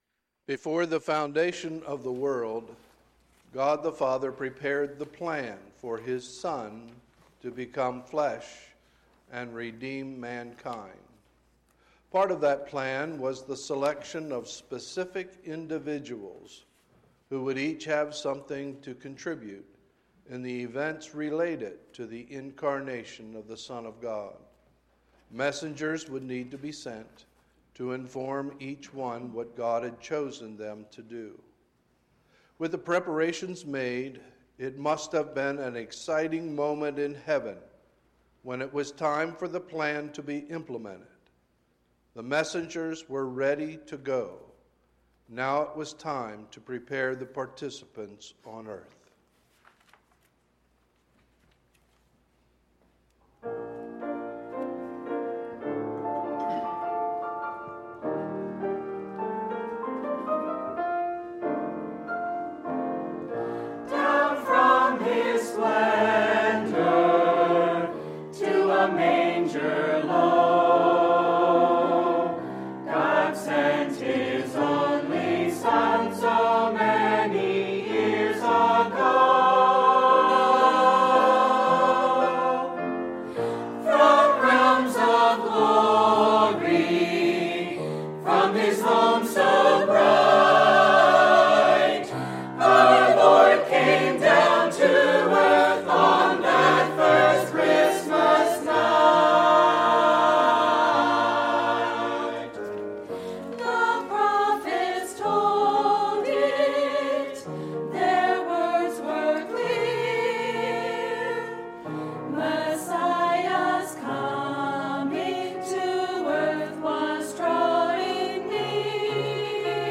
Sunday, December 22, 2019 – Sunday Morning Service